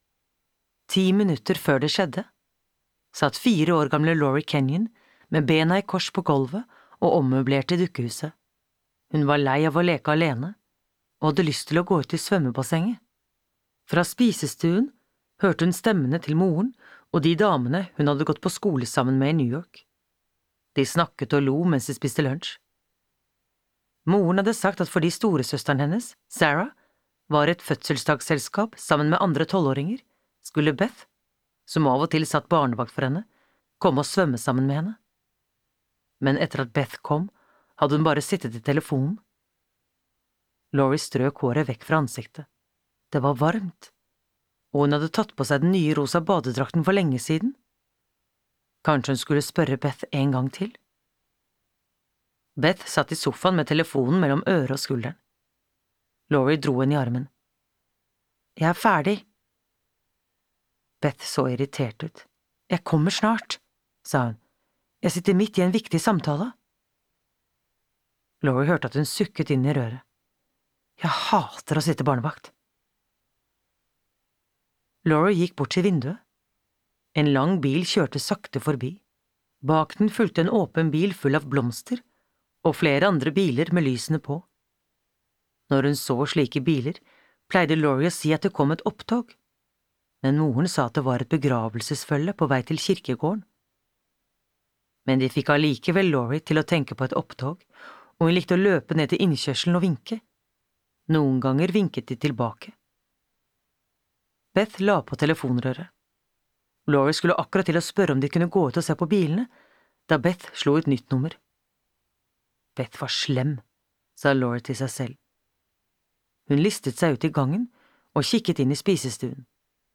Stemmenes rop (lydbok) av Mary Higgins Clark